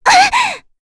Frey-Vox_Damage_02.wav